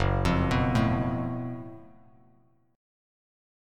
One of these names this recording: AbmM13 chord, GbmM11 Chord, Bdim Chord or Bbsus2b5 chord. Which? GbmM11 Chord